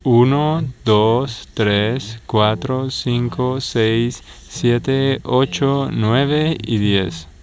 Comparison with other researchers' results: Convolutive Mixtures
second separated signal